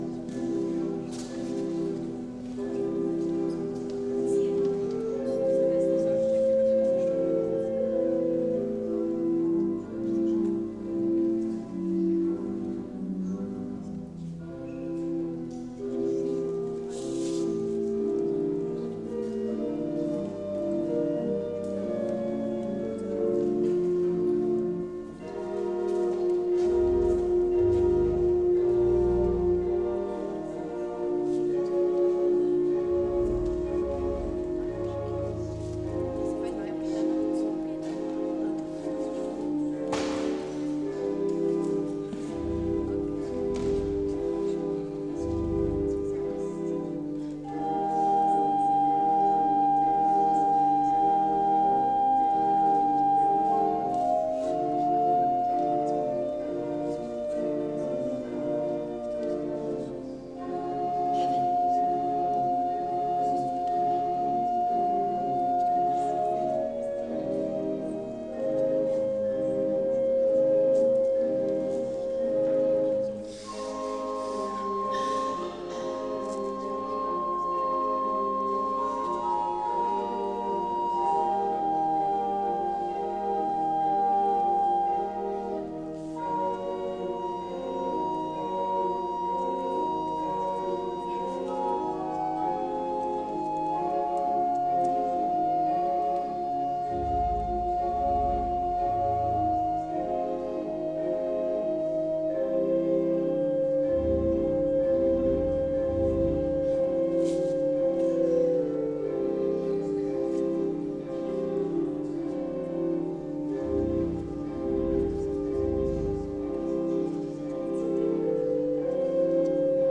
Aktuelle Predigt